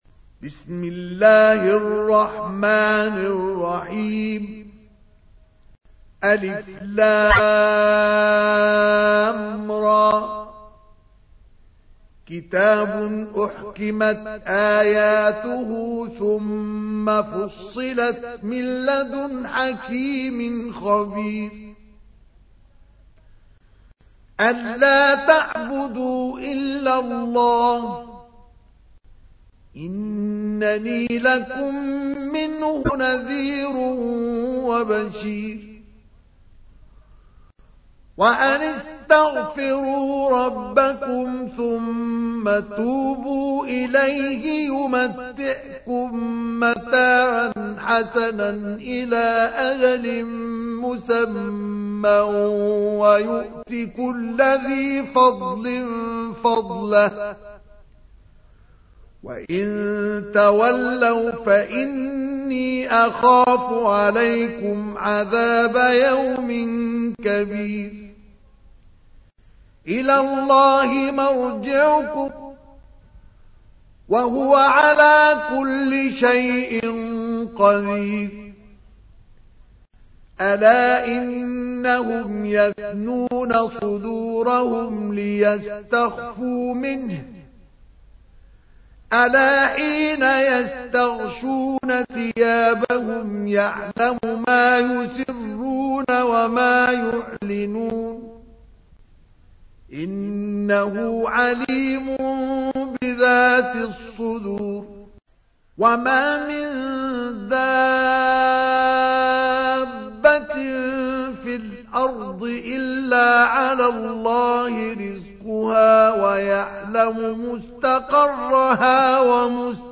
تحميل : 11. سورة هود / القارئ مصطفى اسماعيل / القرآن الكريم / موقع يا حسين